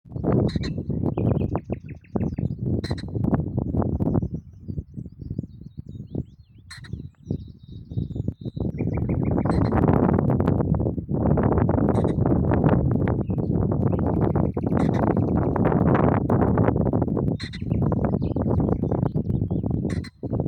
Fasan
Auf Borkum hörte ich meistens erst einen Fasan rufen, bevor ich ihn sah.
Voegel-auf-Borkum-4-Fasan-Nachtigall.mp3